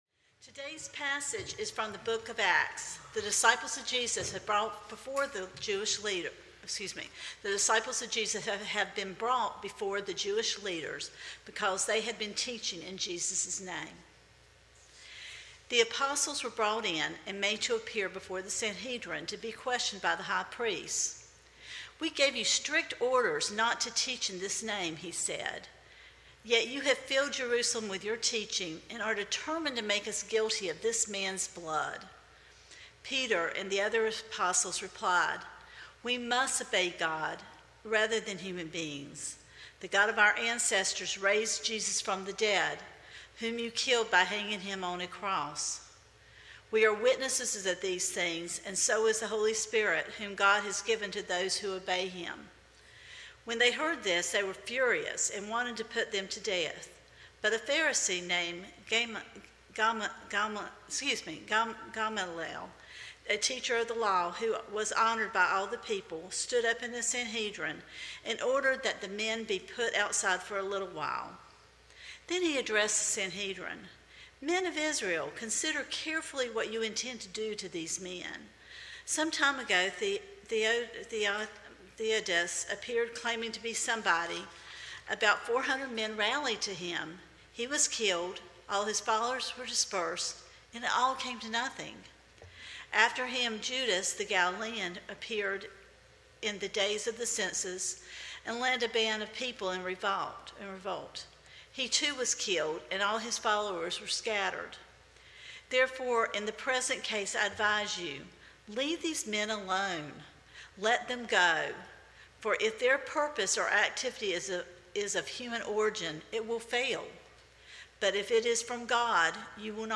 Sermon Reflections: Who has been a significant influencer in your faith journey?